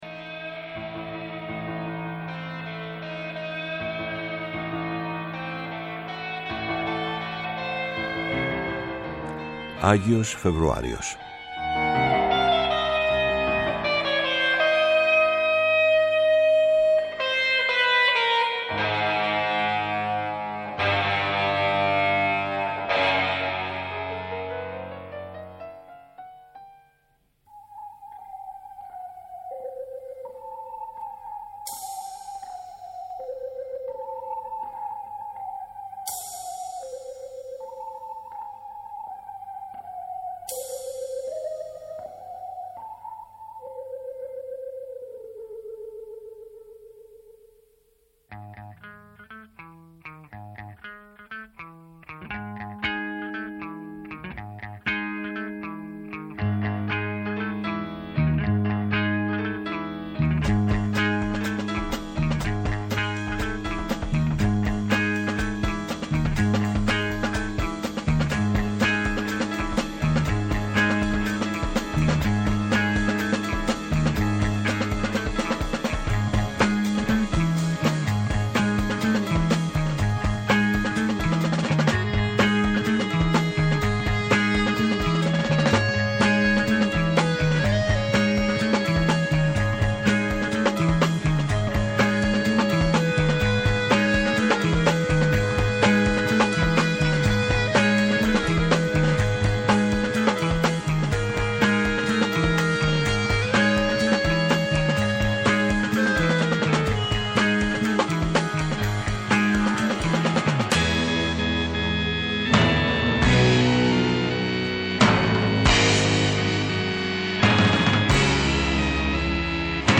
ΜΟΥΣΙΚΗ